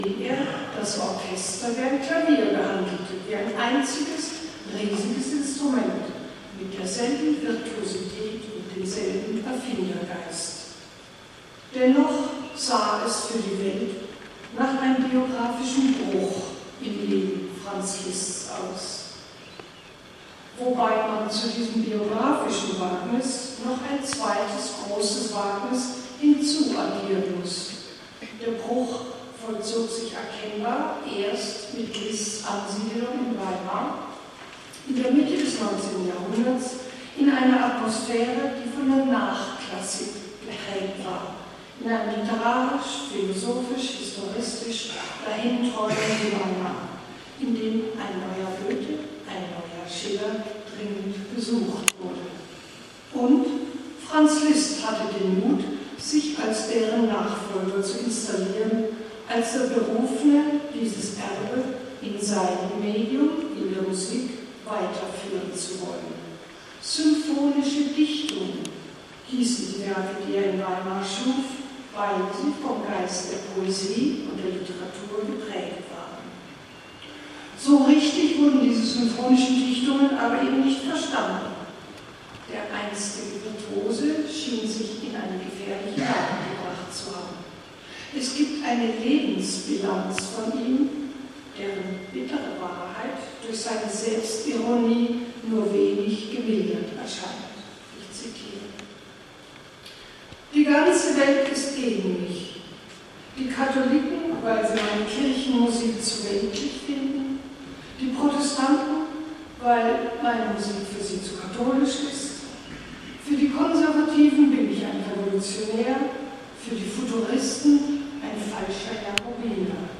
Im Achteckhaus von Schloss Sondershausen wurde am gestrigen Abend die Festwoche zur 4. Liszt-Biennale Thüringen, ein Höhepunkt im Musikleben Thüringens eröffnet...
Nike Wagner, die Ururenkelin von Franz Liszt hielt als Schirmherrin der Biennale die Gastrede.
Franz Liszt war nicht leicht zu fassen, wie dieser Redeauszug von Nike Wagner zeigt. Sie würdigte Liszt als großen Musiker dem besonders Europa in seiner Musik wichtig war.